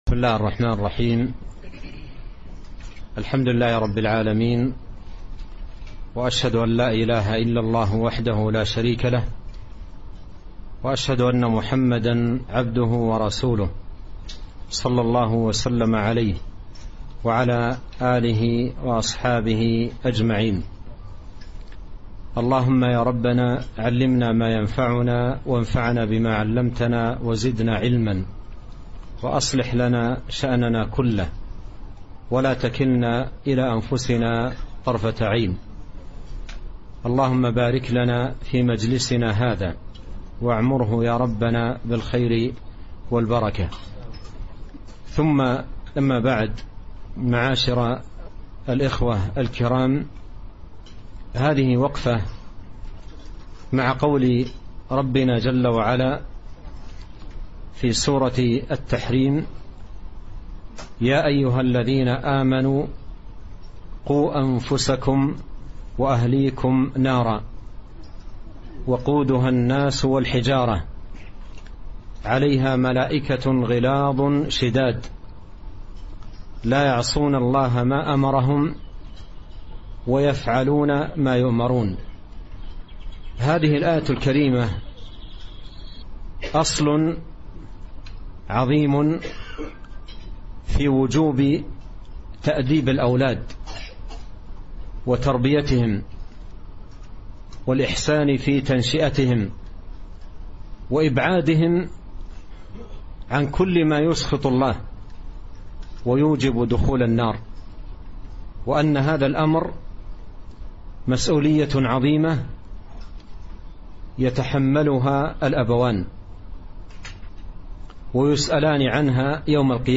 كلمة - مسؤولية الأبوين تجاه الأسرة